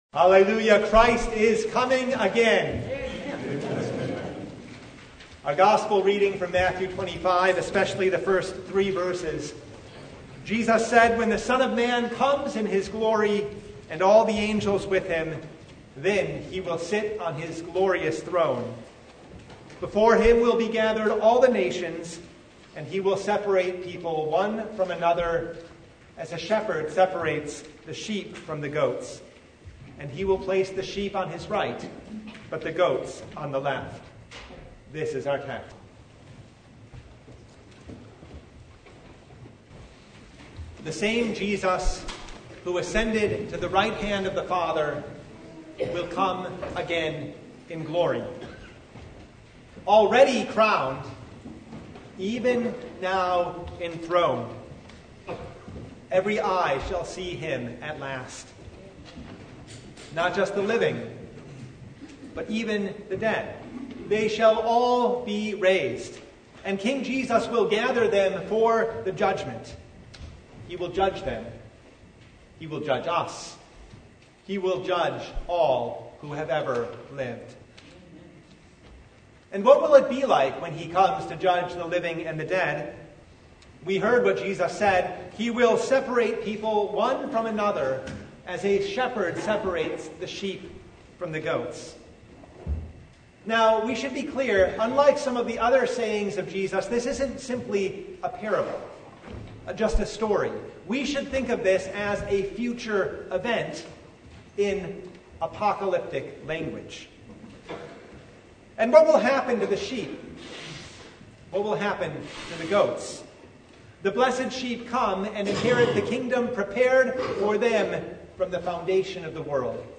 Sermon from Christ the King (2023)